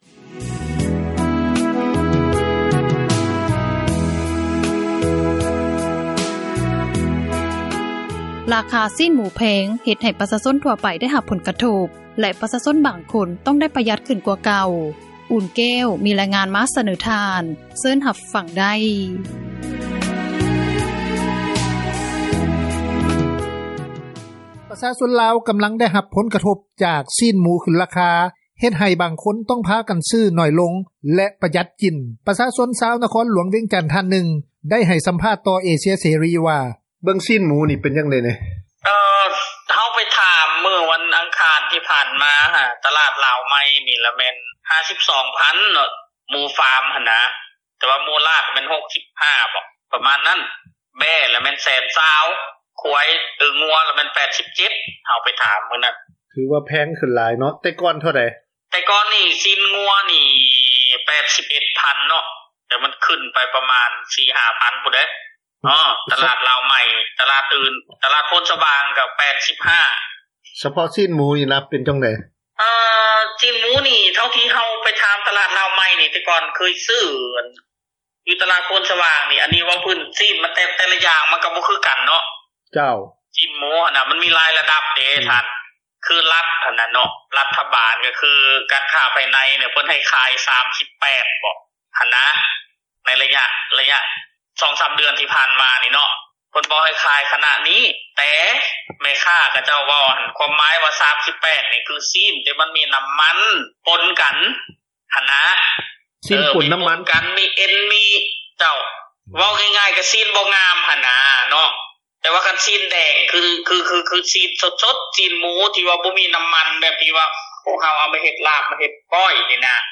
ປະຊາຊົນລາວ ກຳລັງໄດ້ຮັບຜົລກະທົບຈາກຊີ້ນໝູ ຂຶ້ນລາຄາ ເຮັດໃຫ້ບາງຄົນ ຕ້ອງພາກັນຊື້ໜ້ອຍລົງ ແລະ ປະຢັດກິນ. ປະຊາຊົນຊາວນະຄອນຫລວງວຽງຈັນ ທ່ານນຶ່ງ ໄດ້ກ່າວໃຫ້ສັມພາດ ຕໍ່ເອເຊັຽເສຣີວ່າ: